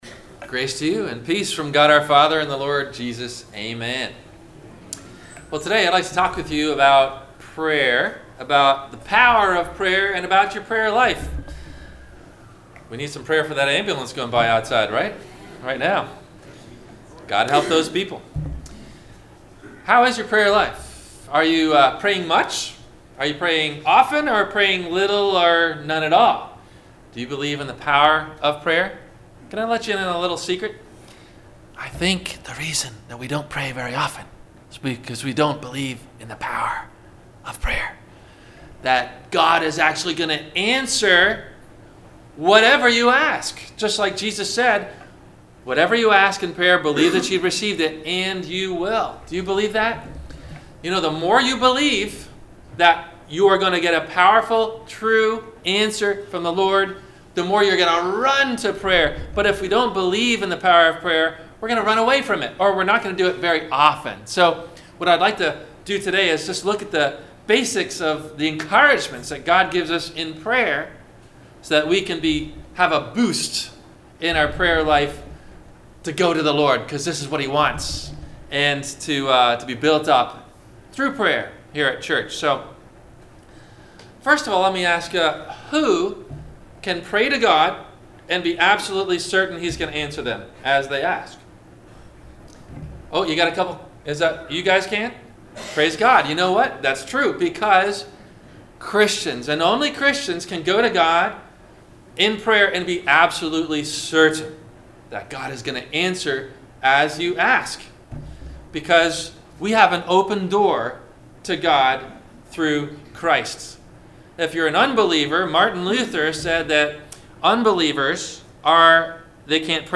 - Sermon - March 17 2019 - Christ Lutheran Cape Canaveral